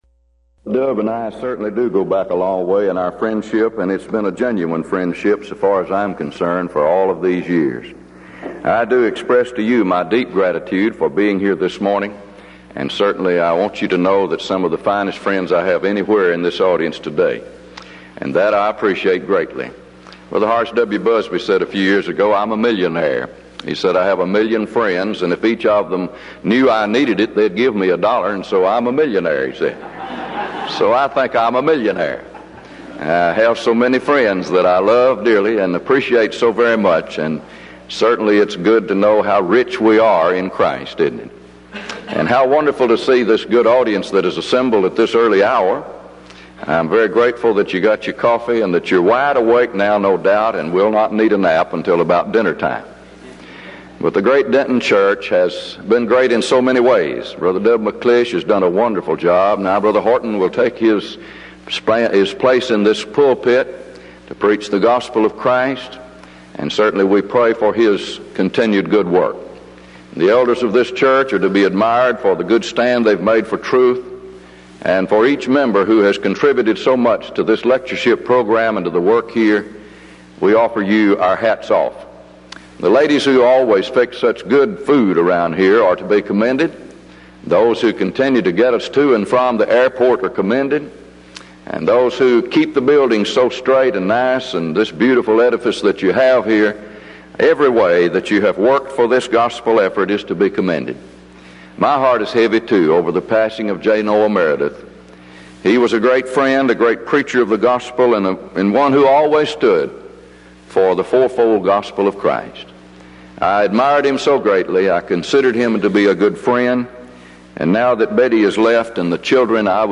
Event: 1992 Denton Lectures